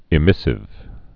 (ĭ-mĭsĭv)